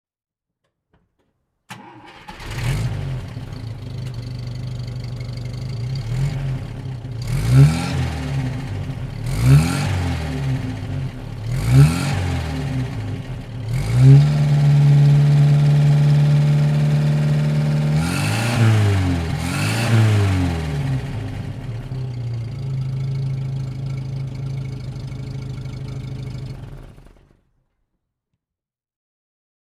Motorsounds und Tonaufnahmen zu Opel Fahrzeugen (zufällige Auswahl)
Opel_Rekord_P2_1962.mp3